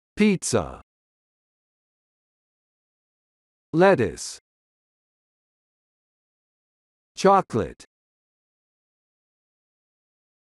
Pronunciation.mp3